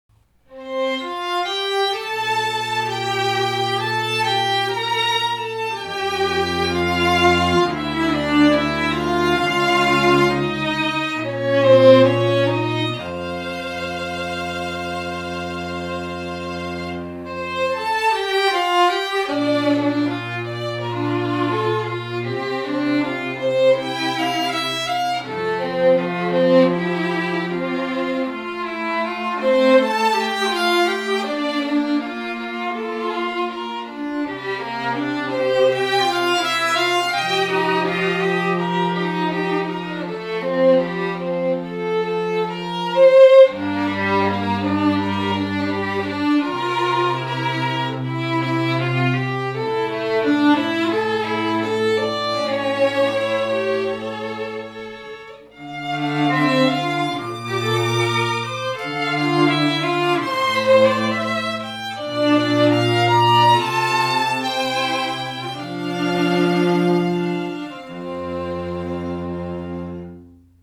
STRING TRIO SAMPLES